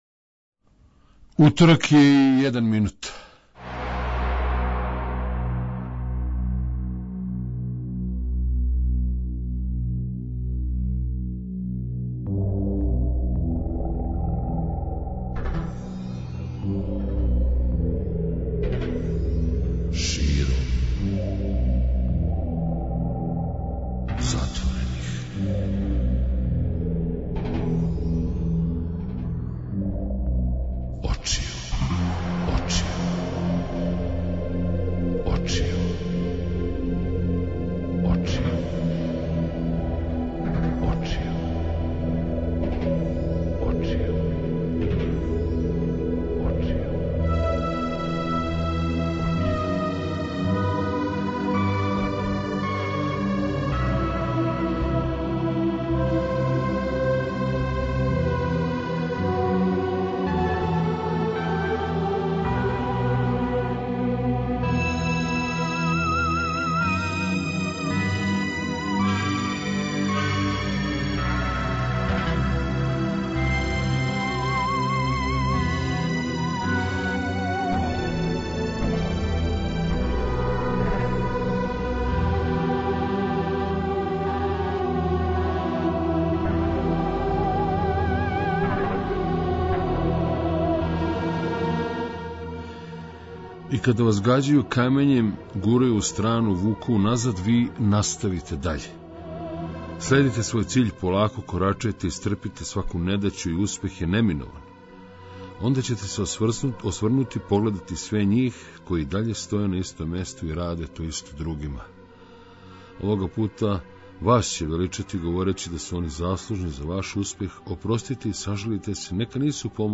Ноћас на таласима Београда 202 још једна бајка, музичка, препуна снаге, речи, нота, неких порука које ће настати у току ноћи исписане по небу а и шире.